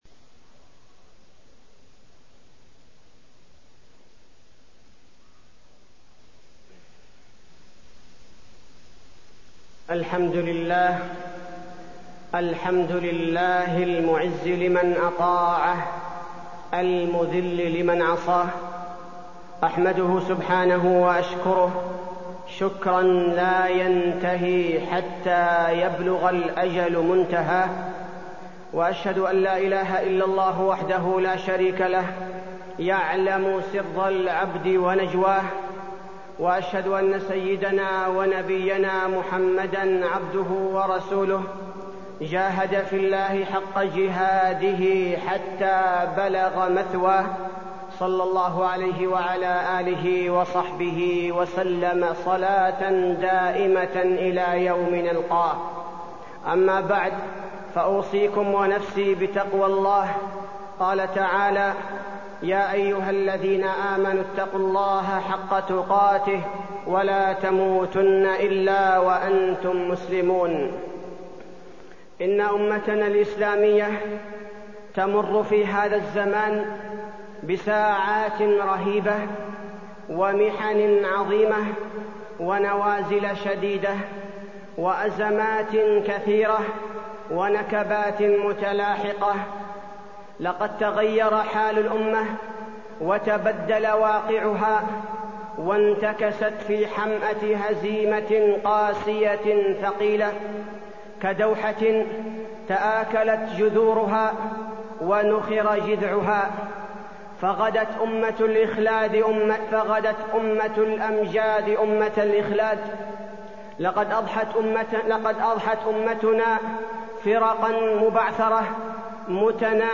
تاريخ النشر ١٦ جمادى الأولى ١٤٢٣ هـ المكان: المسجد النبوي الشيخ: فضيلة الشيخ عبدالباري الثبيتي فضيلة الشيخ عبدالباري الثبيتي الجراح الفلسطينية